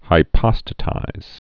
(hī-pŏstə-tīz)